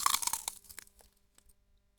Crunch! …That loud, crisp snap as my teeth sink into a fresh, juicy apple.
The skin breaks, the flesh pops, and the sound echoes in the silence.
Each bite is clean, sharp, and satisfying.”
오히려 과자를 먹을 때 생기는 소리도 있는 것 같고 가장 사과를 베어 물때 어떤 효과음이 잘 어울릴지 고민하고 선택하시면 되겠죠?